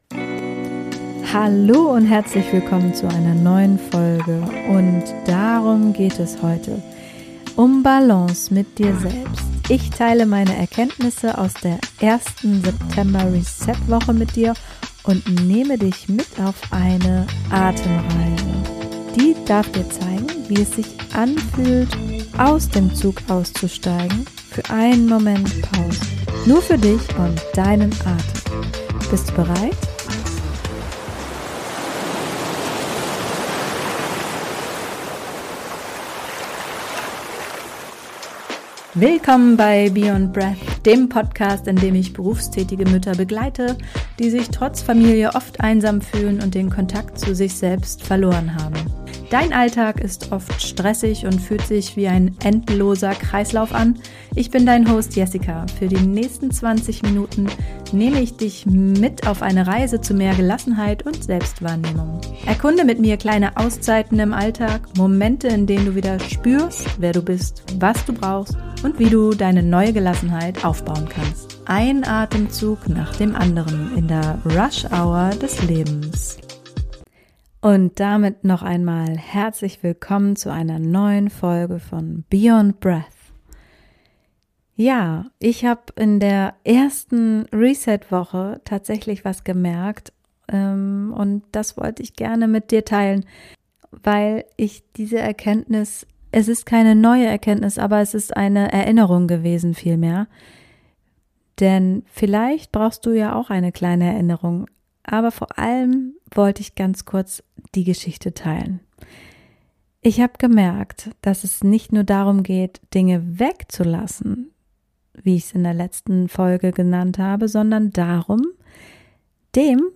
Das erwartet dich heute *Eine geführte Atemreise* Lehn dich zurück, gönn dir diese Auszeit und finde mit deinem Atem zurück in deine Balance.